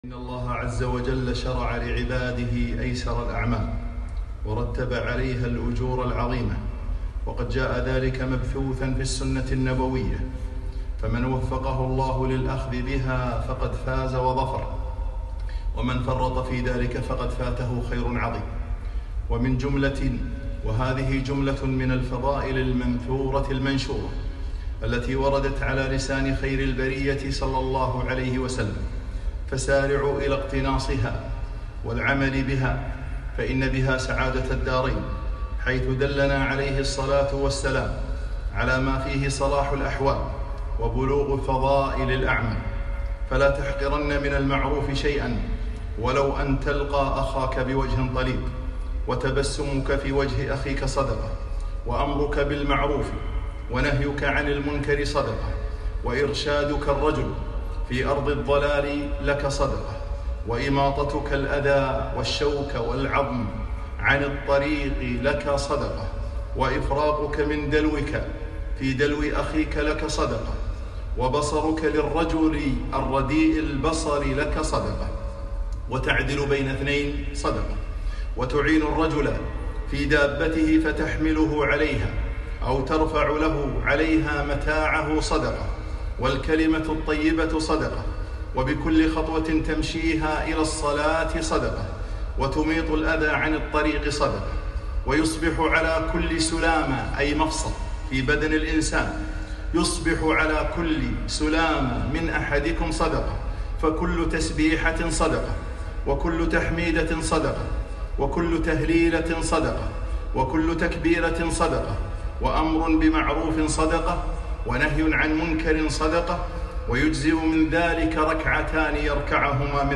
خطبة - من قبس السنة